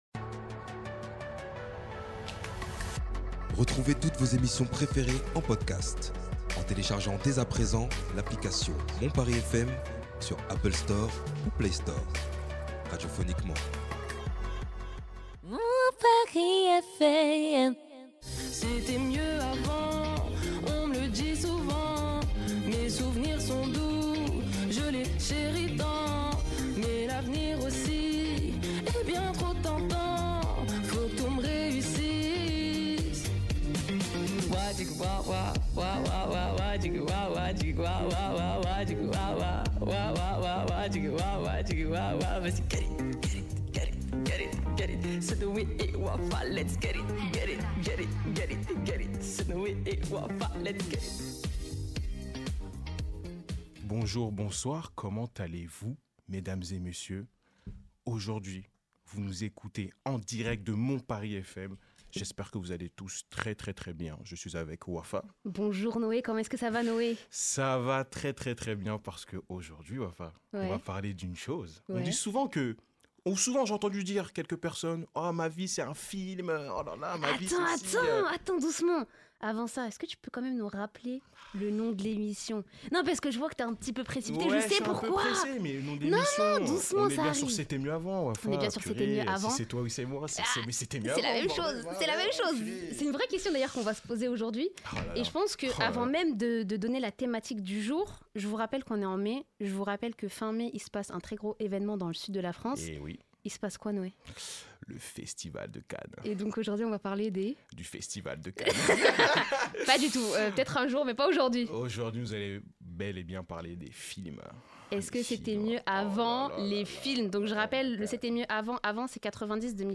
Pour cette émission, nous nous focaliseront sur les films qui ont marqués les 3 dernières décennies, nos préférences personnelles avant d’enchaîner avec un blind test de qualité ! Les arguments et statistiques seront de mise pour prouver si oui ou non c’était mieux avant. Nous voulons savoir si la nostalgie s’allie aux faits pour attester que c’était mieux avant ou si les nouveaux générations produisent de meilleur film qu’avant.